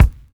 DISCO 13 BD.wav